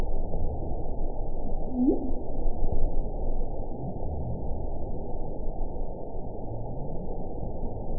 event 919142 date 12/25/23 time 19:36:17 GMT (1 year, 5 months ago) score 8.71 location TSS-AB05 detected by nrw target species NRW annotations +NRW Spectrogram: Frequency (kHz) vs. Time (s) audio not available .wav